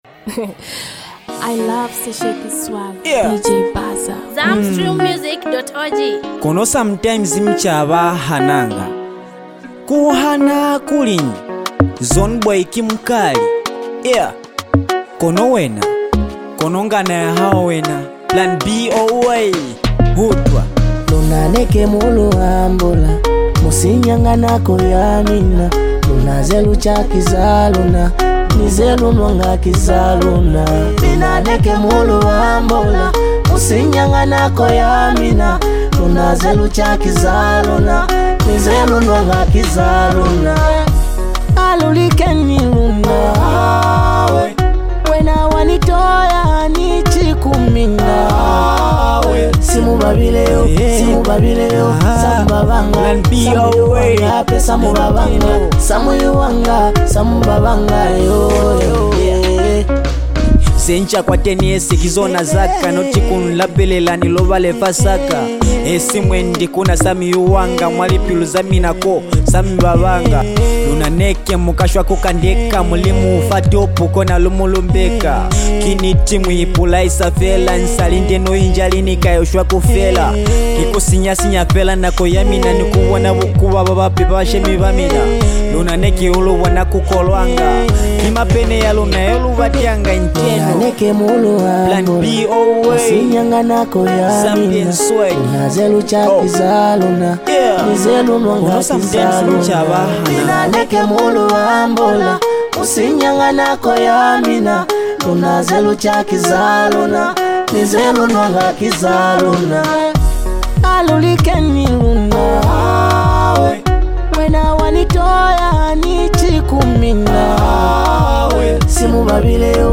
modern sonic energy
expressive delivery and raw emotional depth
bold cadence and impactful bars